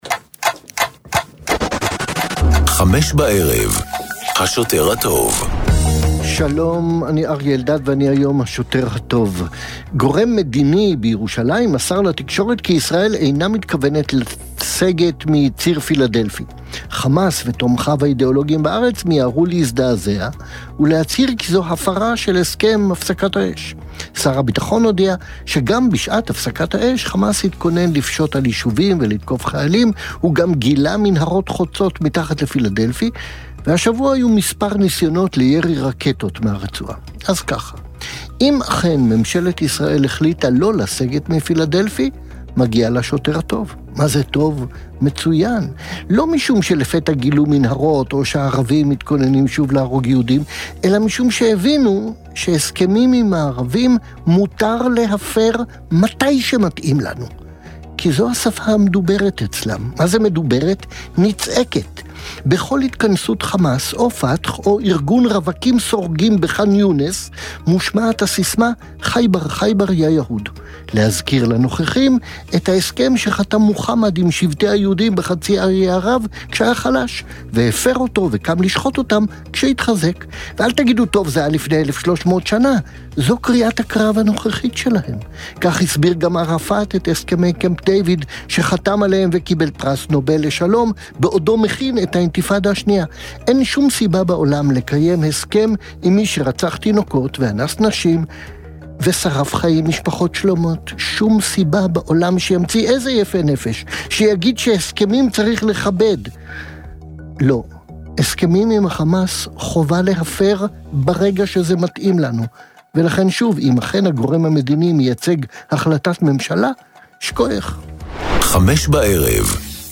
בן כספית, מהעיתונאים הבכירים והבולטים כיום במדינת ישראל, ופרופסור אריה אלדד, רופא, פובליציסט וכמובן חבר כנסת לשעבר מטעם האיחוד הלאומי ועוצמה לישראל, מגישים יחד תכנית אקטואליה חריפה וחדה המורכבת מריאיונות עם אישים בולטים והתעסקות בנושאים הבוערים שעל סדר היום. השילוב הנפיץ של השניים מייחד את התכנית ומוביל כל דיון לכיוונים לא צפויים.